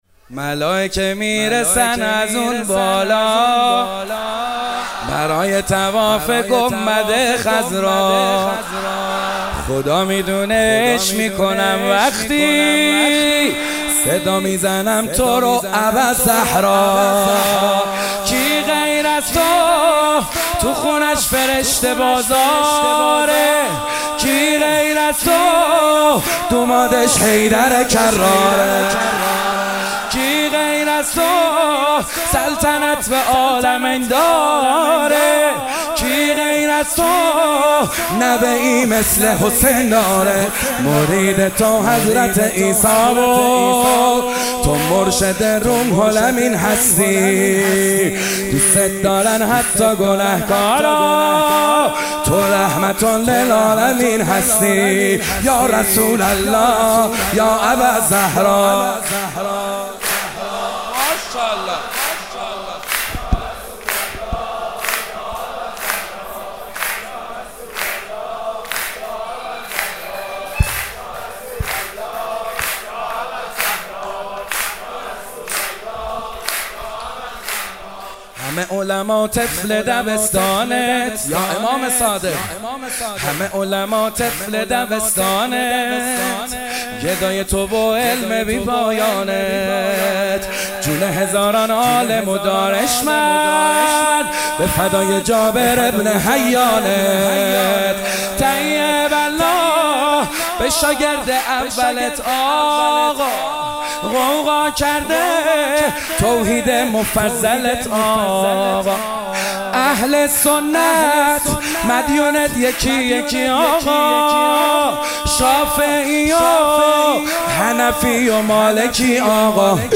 مولودی
همزمان با فرارسیدن هفدهم ربیع الاول، ایام ولادت پیامبر رحمت و مهربانی و همچنین ششمین اختر تابناک امامت حضرت صادق علیه السلام، فایل صوتی گلچین مولودی با نوای مداحان اهل بیت (ع) را می شنوید.